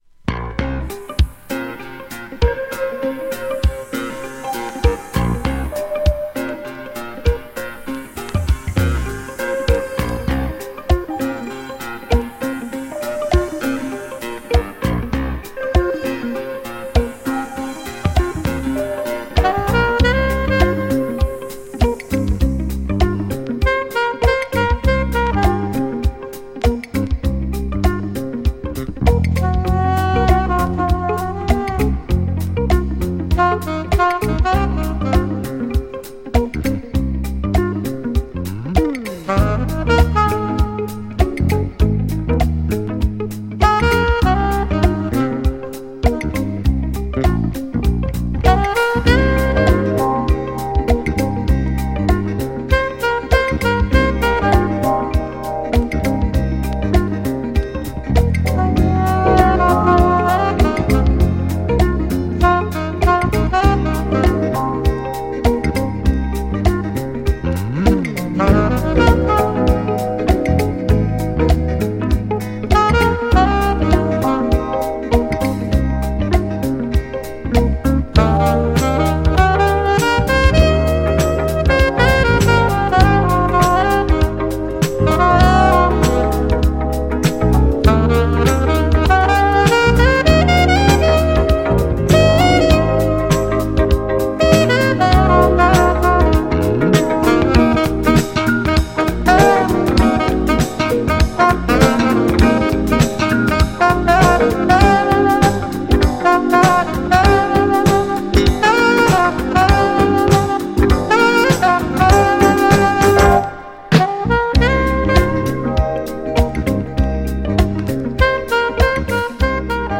小気味良いテンポのアーバンなJAZZ INST。
GENRE Dance Classic
BPM 111〜115BPM